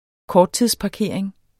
Udtale [ ˈkɒːdtiðs- ]